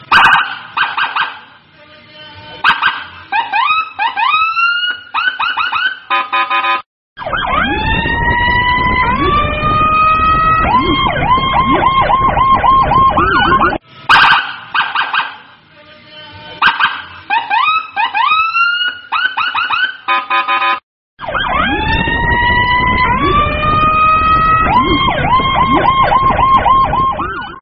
Nada dering Suara Mobil Patwal viral TikTok
Keterangan: Unduh nada dering suara mobil patwal viral TikTok dalam format MP3 untuk WA dan semua jenis HP.
nada-dering-suara-mobil-patwal-viral-tiktok-id-www_tiengdong_com.mp3